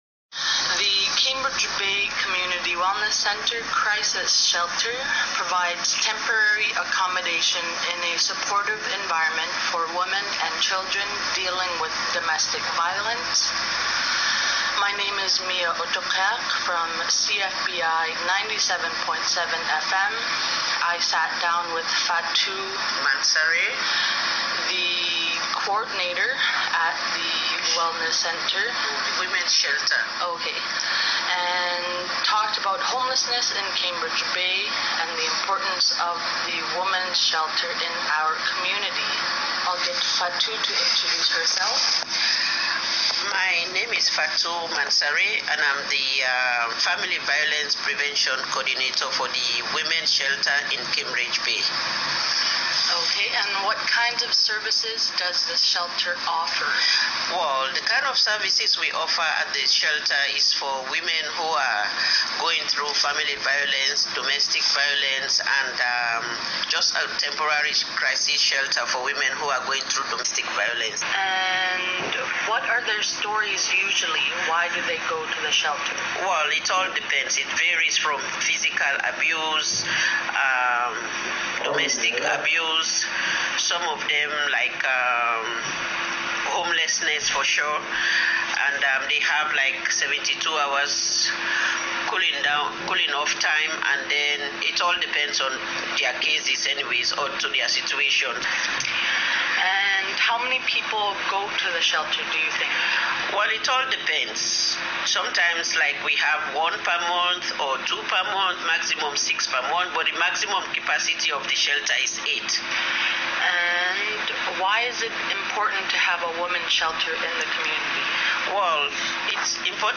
Recording Location: Cambridge Bay, Nunavut
Type: News Reports